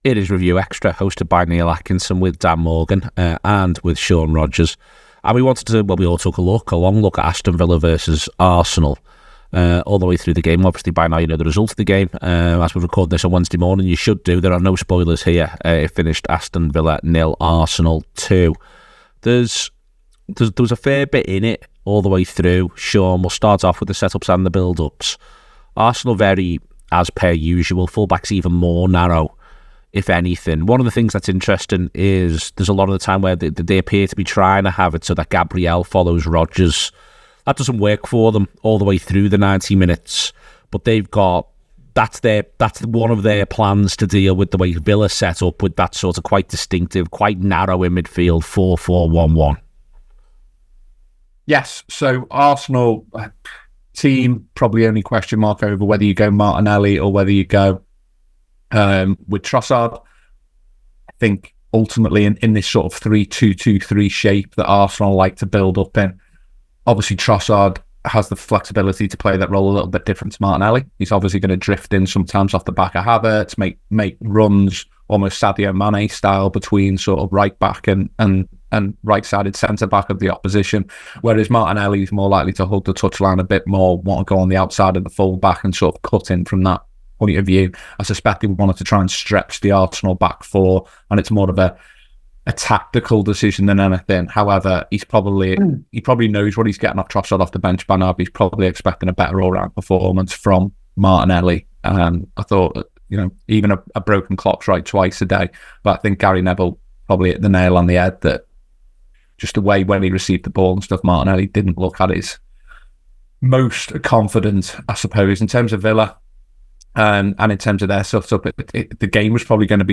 Below is a clip from the show – subscribe for more review chat around Aston Villa 0 Arsenal 2…